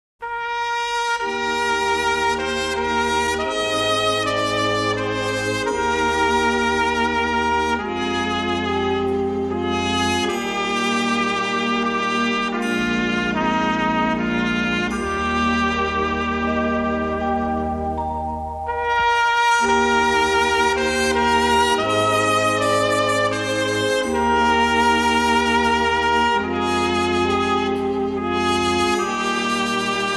Genre: Around The World - Germany
Music from the Rhine River.